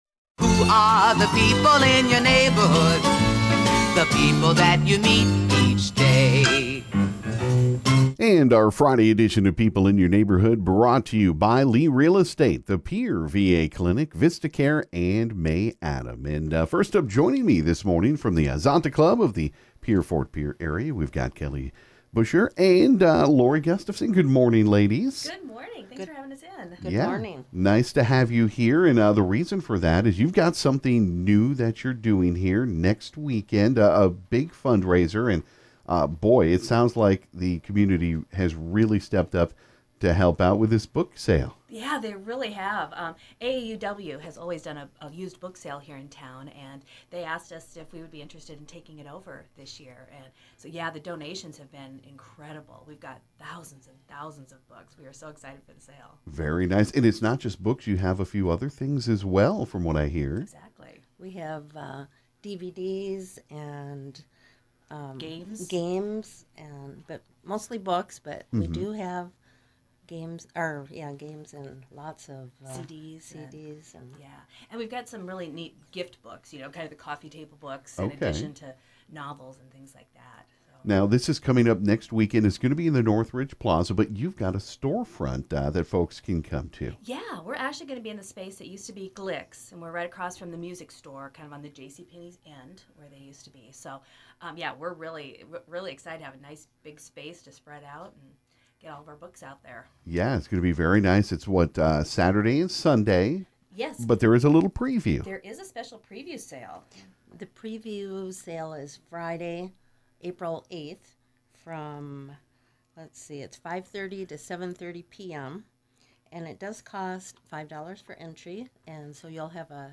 This morning at the KGFX studio it was a busy one.